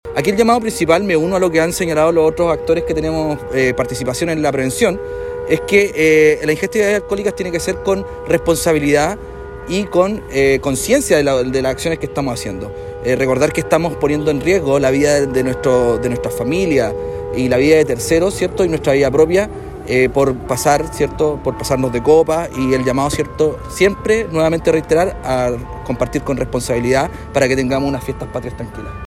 El uniformado señaló que la ingesta de bebidas alcohólicas debe ser responsable, pues al no respetar la normativa en cuanto a la ingesta de alcohol y conducción puede desencadenar en accidentes de tránsito con consecuencias fatales.